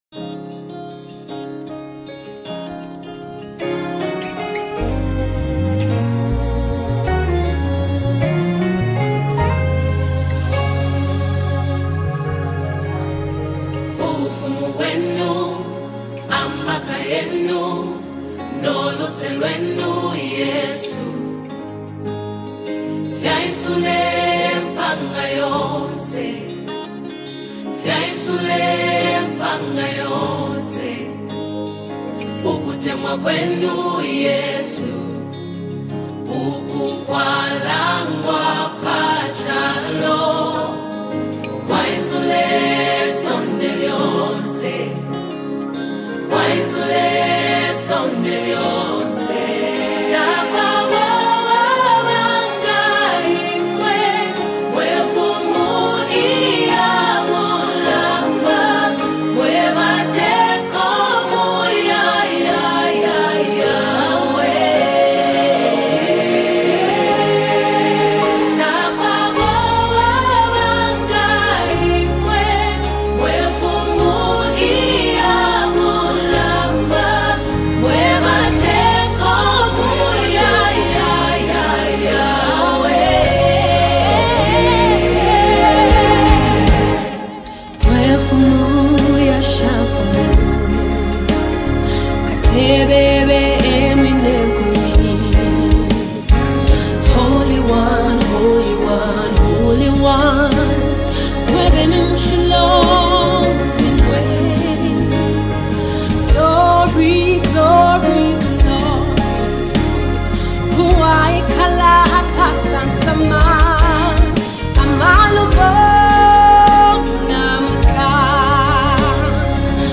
soulful new worship anthem